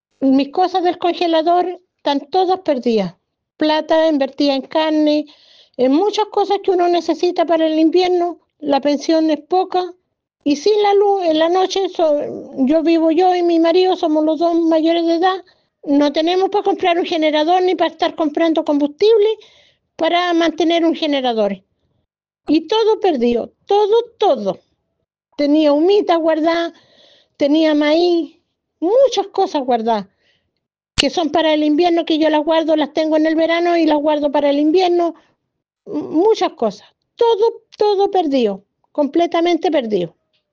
Una vecina del sector rural describió el impacto económico devastador en su hogar.
vecina-corte-luz-los-angeles.mp3